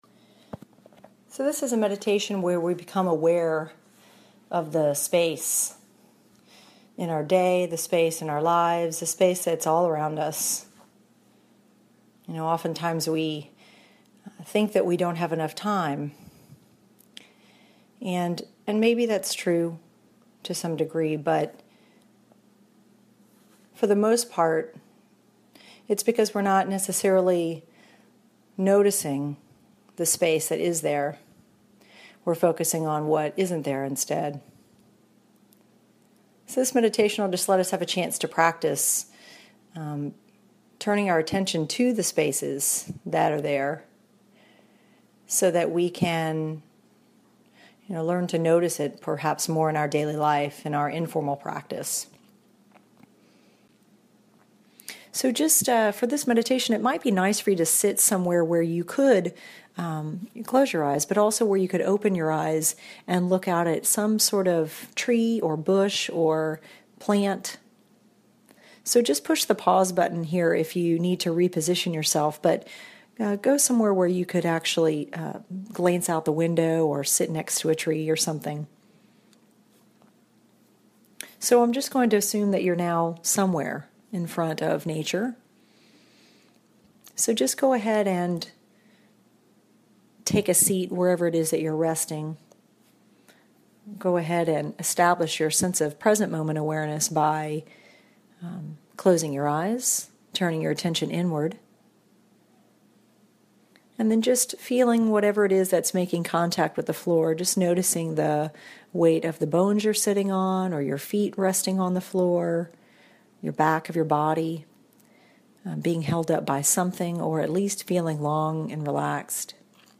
This is an 18-minute guided meditation about becoming aware of the space that actually does exist. If you feel you don't have enough time in your day to get everything done, this would be a great meditation to practice.